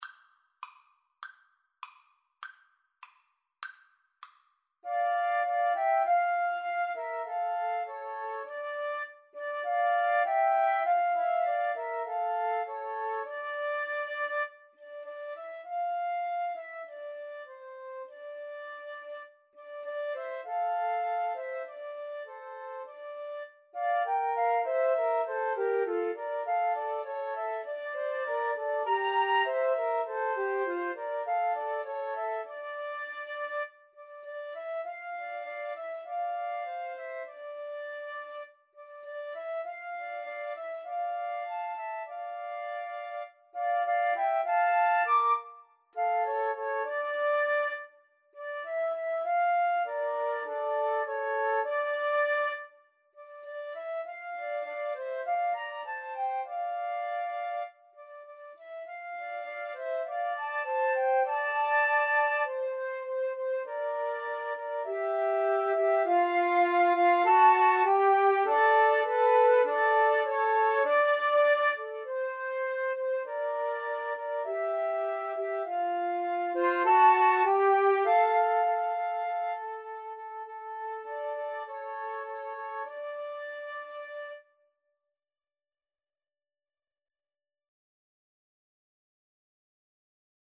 Moderato
2/4 (View more 2/4 Music)
Arrangement for Flute Trio
Classical (View more Classical Flute Trio Music)